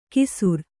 ♪ kisur